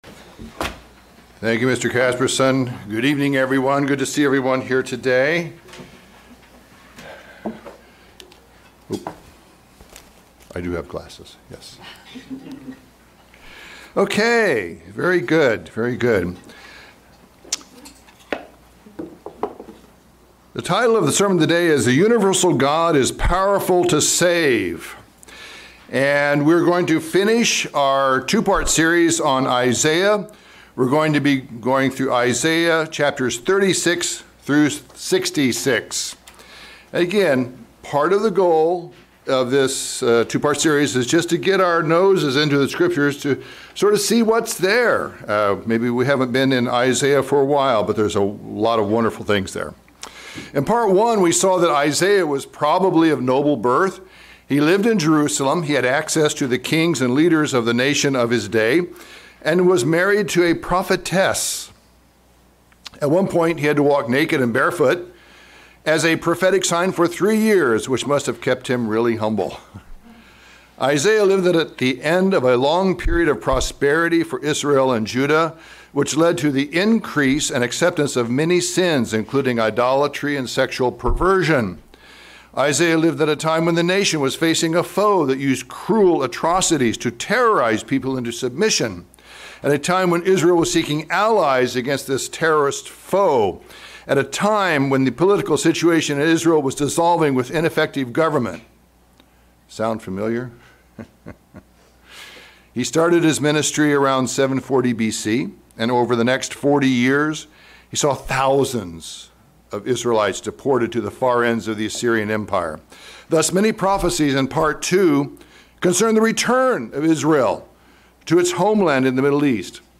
Sermon given on 8/28/2021. Today we will survey Isaiah chapters 36-66 using themes to organize the material in the various chapters.